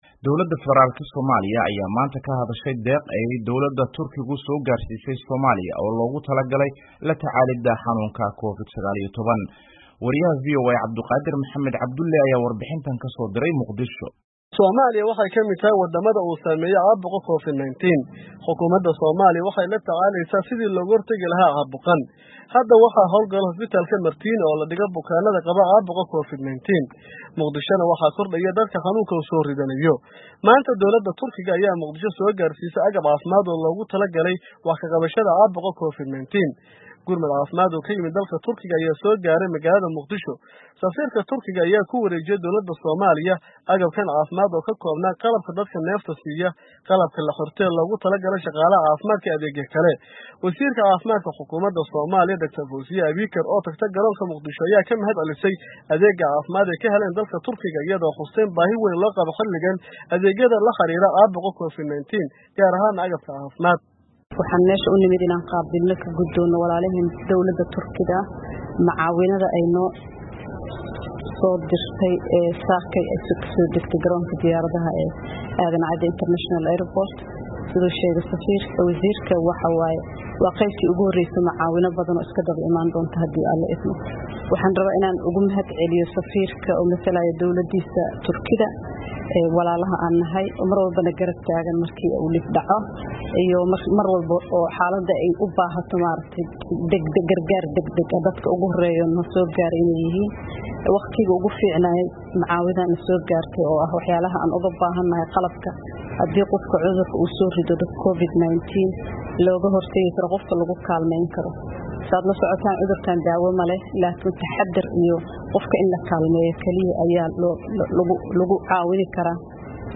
Warbixintaan waxaa Xamar kasoo diray,